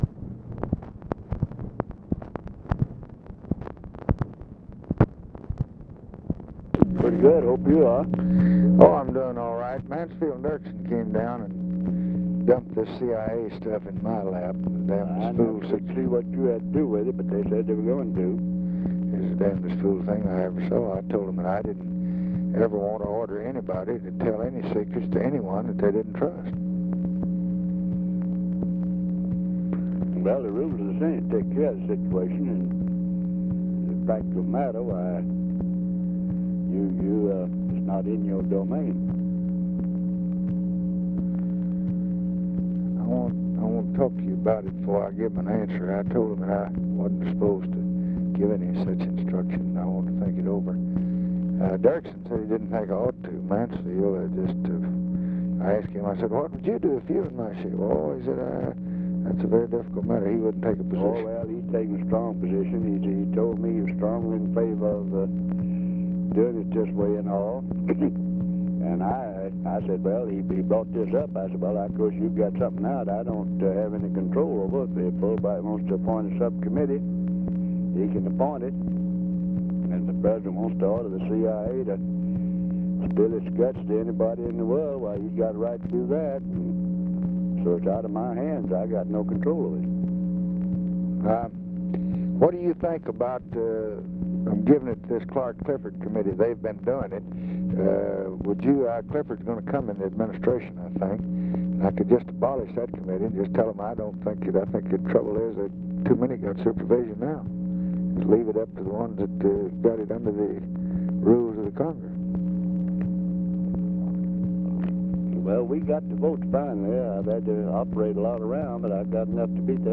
Conversation with RICHARD RUSSELL, June 3, 1966
Secret White House Tapes